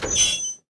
train-brake-screech-2.ogg